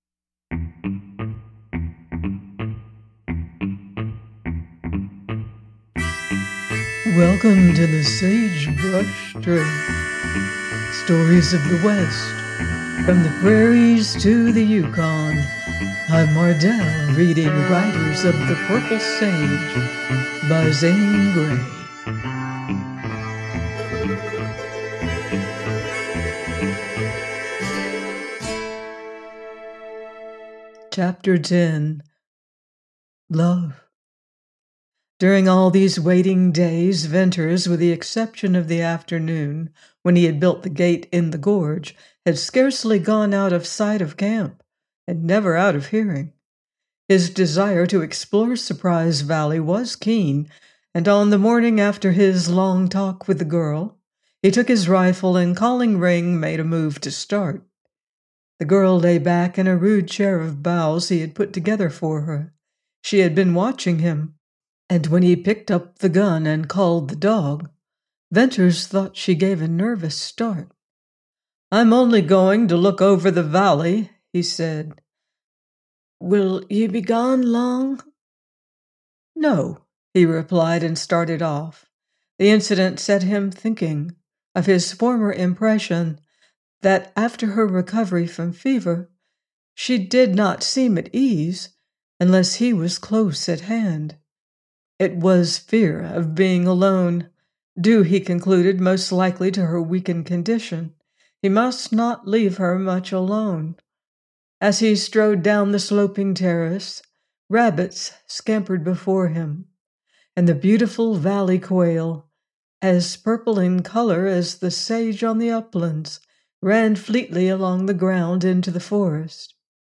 Riders Of The Purple Sage – Ch. 10: by Zane Grey - audiobook